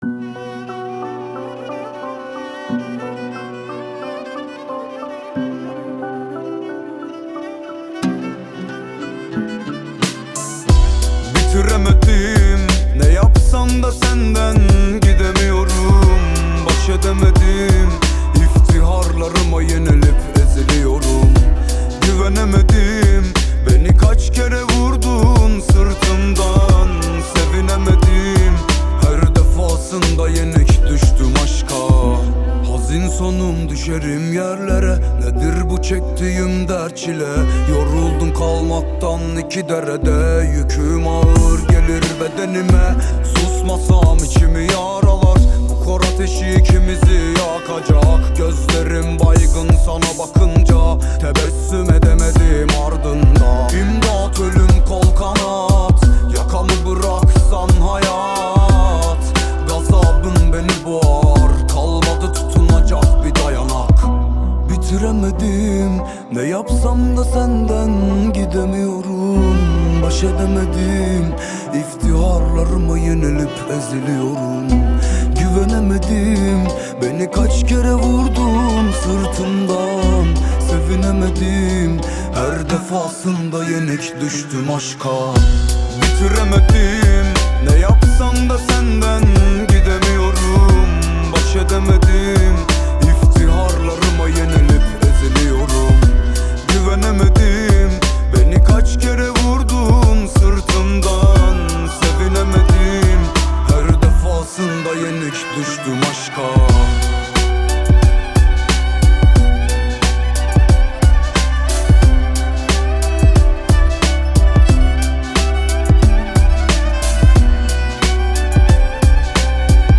Turki Muzik